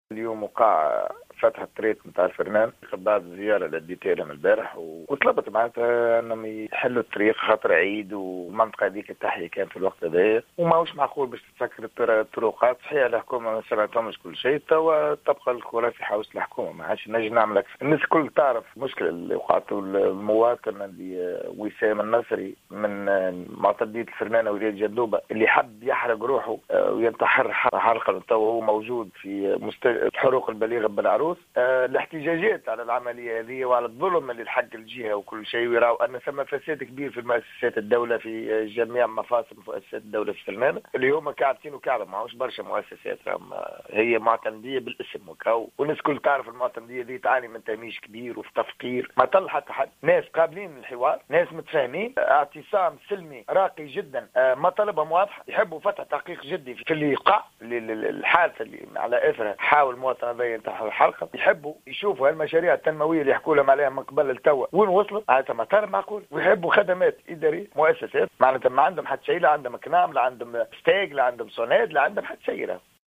أكد فيصل التبيني النائب عن حزب صوت الفلاحين في تصريح للجوهرة أف أم اليوم السبت 10 سبتمبر 2016 أنه تم إعادة فتح الطريق الوطنية عدد 17 الرابطة بين جندوبة وطبرقة مرورا بفرنانة على مستوى وادي غزالة بعد زيارة أداها أمس للجهة.